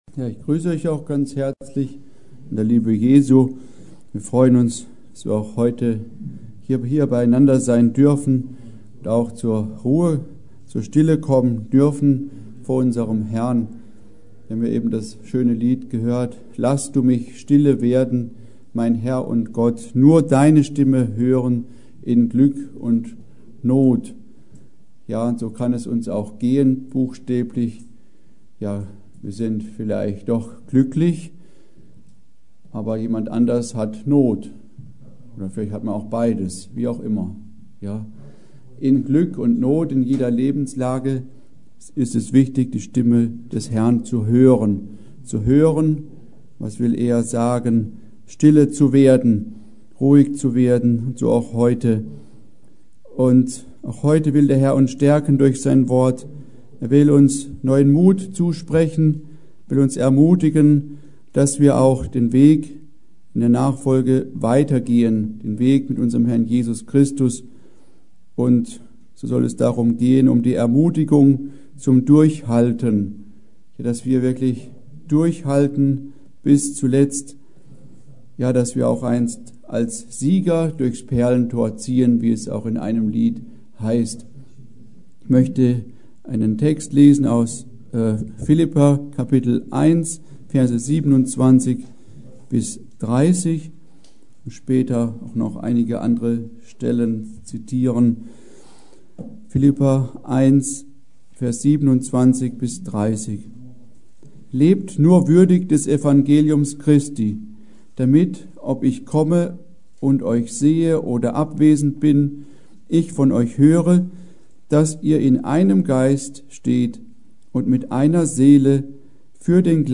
Predigt: Ermutigung zum Durchhalten